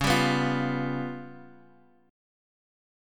Db7b5 Chord
Listen to Db7b5 strummed